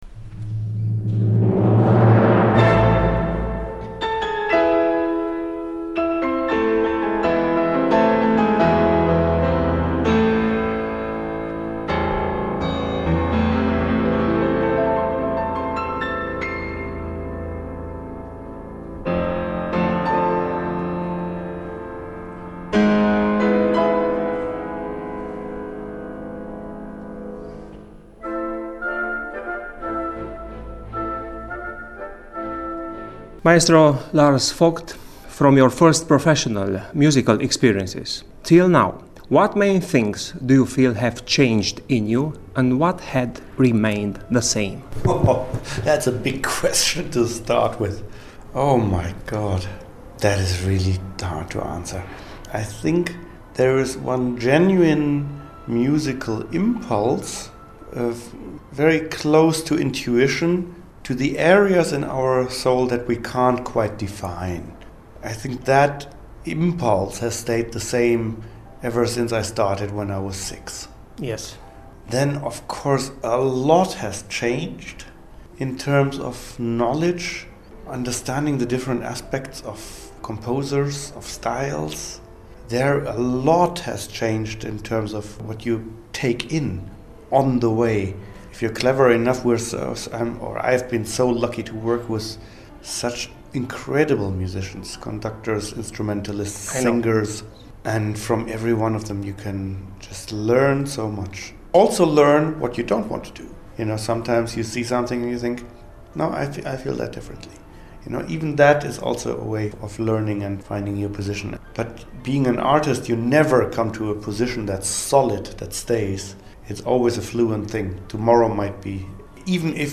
Audiaţi interviul cu pianistul şi dirijorul Lars Vogt
la Bucureşti